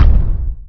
step4.wav